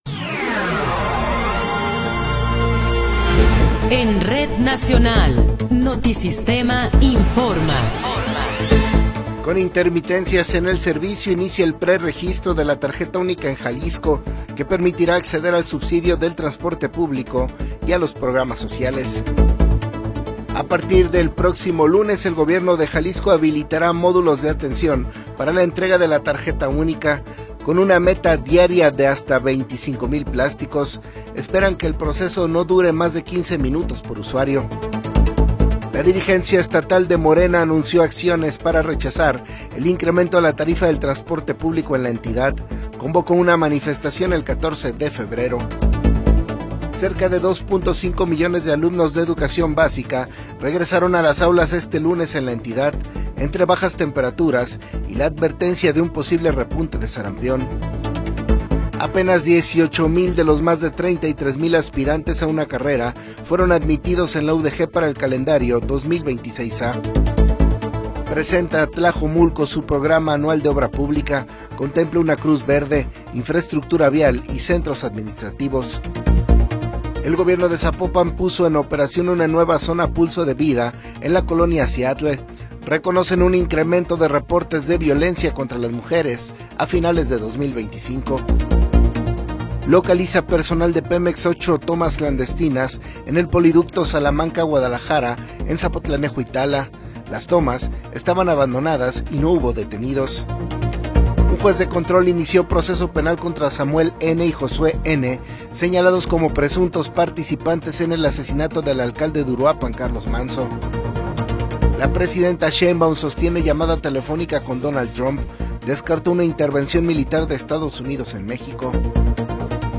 Noticiero 21 hrs. – 12 de Enero de 2026